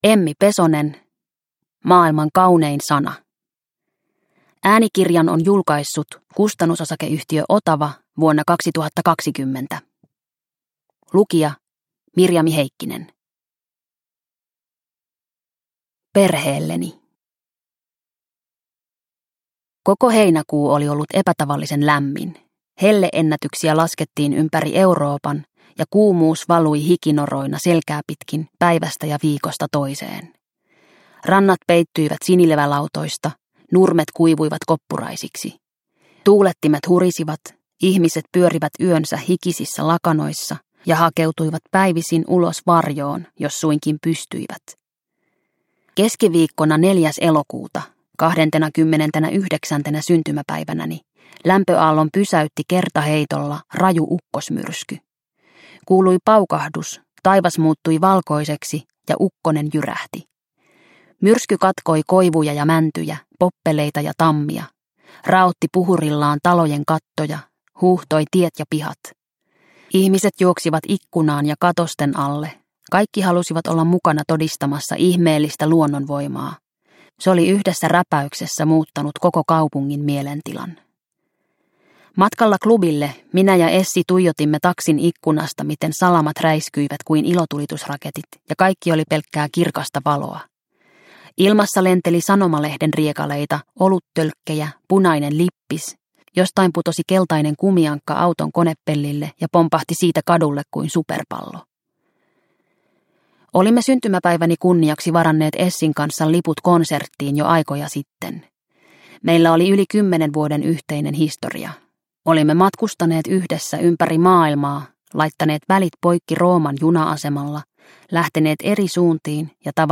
Maailman kaunein sana – Ljudbok – Laddas ner